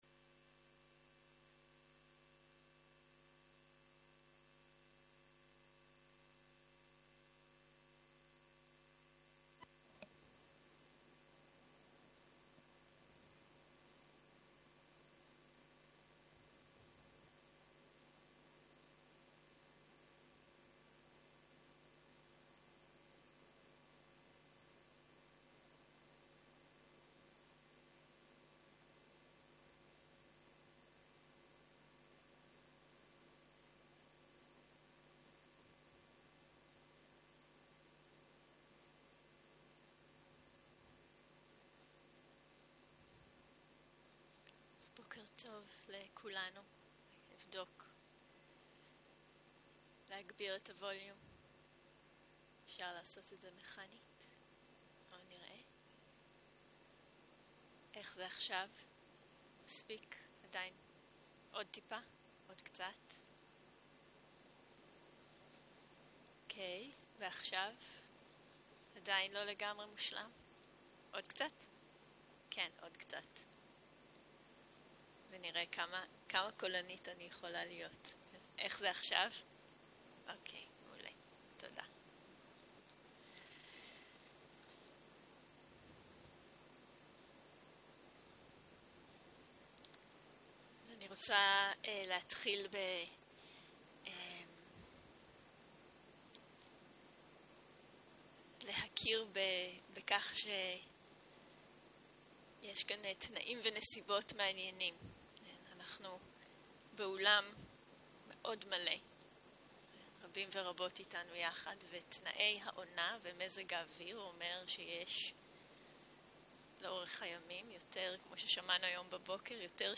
09.04.2023 - יום 3 - בוקר - הנחיות מדיטציה - דוקהה והיפתחות, הרחבת שדה המודעות - הקלטה 6
Guided meditation